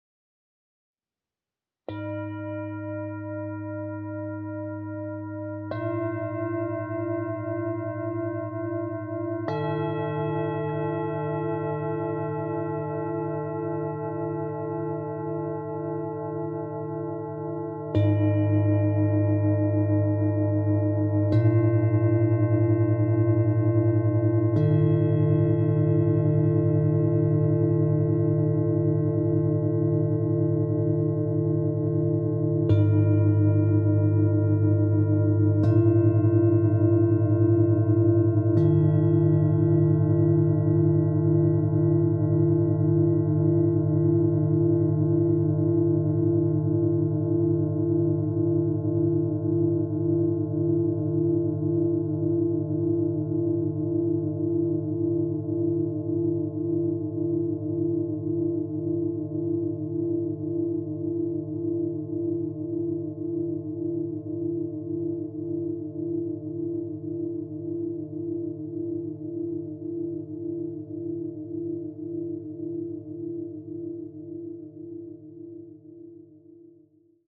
Beim sanften Anschlagen der Sonic Energy Klangschalen entsteht ein faszinierender, vielschichtiger und farbenreicher Klang, der tief in der Seele nachhallt. Über einem satten Grundton entstehen ganze Kaskaden von singenden Obertönen, die frei im Raum schweben und sich im Körper entfalten. Sobald der Klang zu schwingen beginnt, hört er nicht mehr auf; selbst nach einer Minute ist noch ein sanfter Nachhall zu spüren.